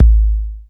BEAT BOX.wav